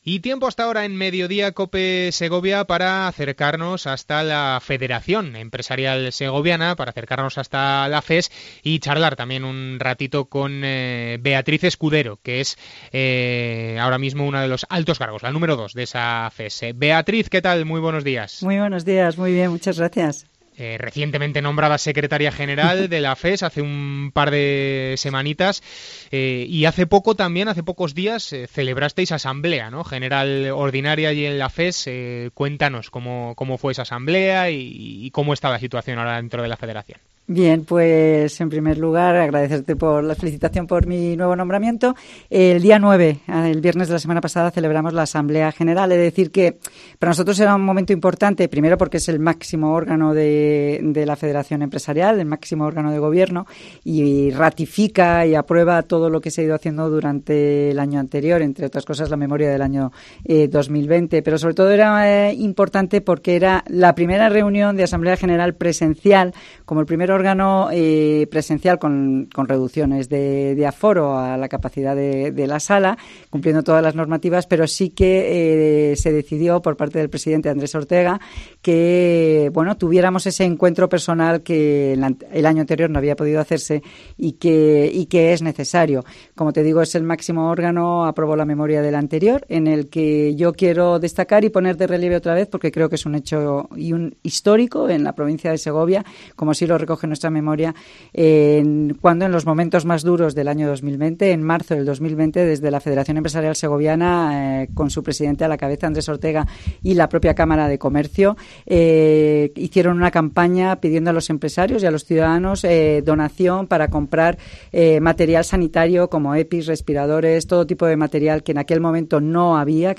Beatriz Escudero, secretaria general de la FES ha visitado COPE Segovia para hablar de la situación de las empresas de la provincia, digitalización y si volver o no a la política